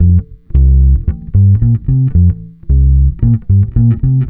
Track 15 - Bass 02.wav